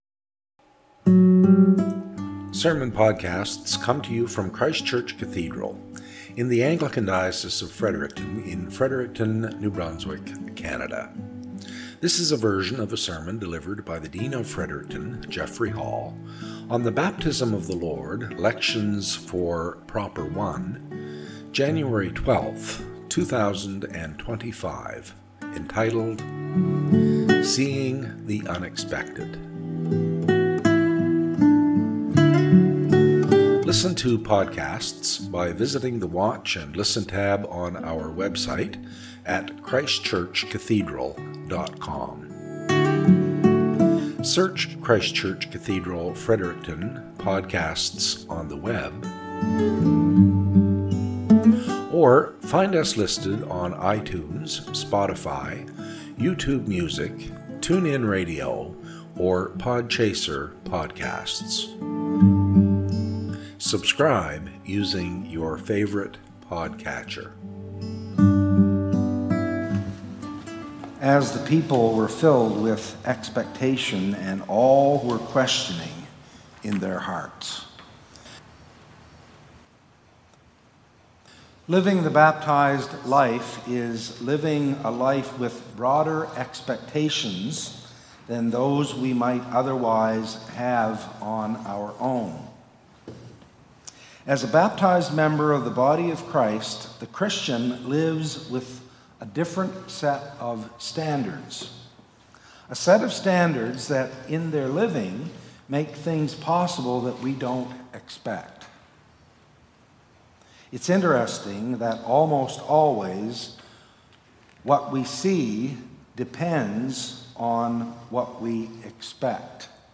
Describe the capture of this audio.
Podcast from Christ Church Cathedral Fredericton